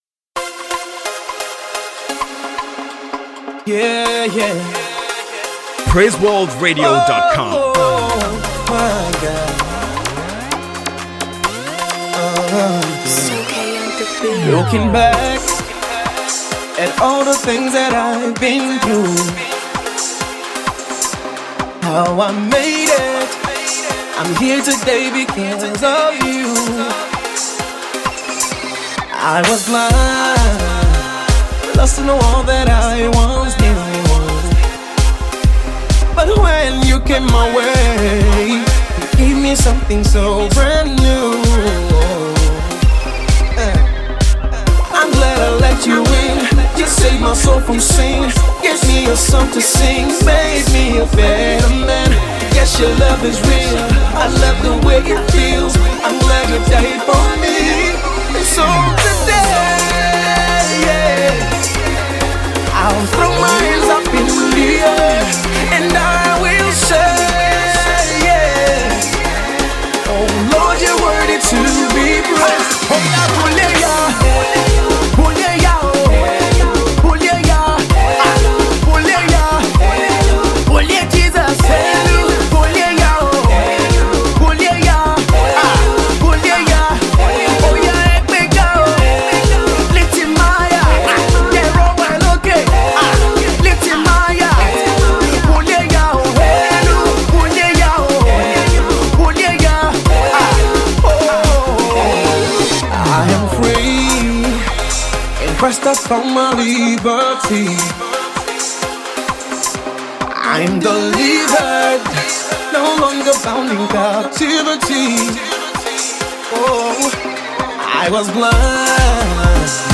with a fine blend of pop and techno.